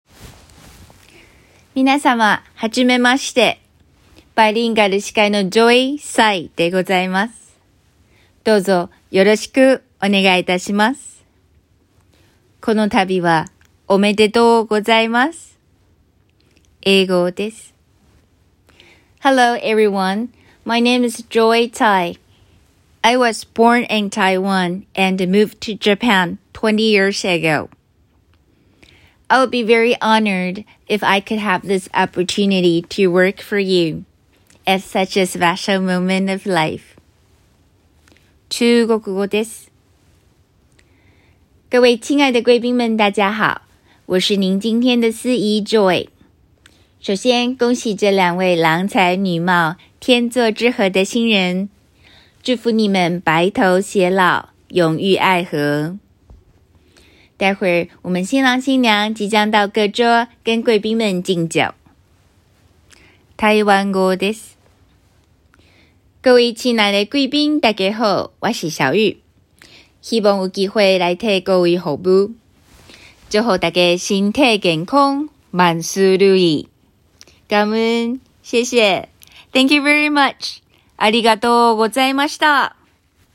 音声サンプル